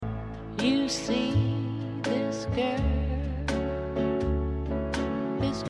un accord suivi du même accord à la quarte
cet accord à la quarte passe lui-même à la quarte